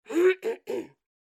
Tiếng Ùm ừ để gây chú ý của người phụ nữ
Thể loại: Tiếng con người
Description: Là tiếng đằng hắng giọng của người đàn ông, nhằm mục đích thu hút sự chú ý, tỏ vẻ khó chịu, khó tính... của người phụ nữ đối diện, âm thanh hắng giọng ừm ừ của chàng trai thể hiện cảm xúc tự tin của con người, sẵn sàng gây sự chú ý với nững người xung quanh, đặc biệt là cô gái trẻ.
Tieng-um-u-de-gay-chu-y-cua-nguoi-phu-nu-www_tiengdong_com.mp3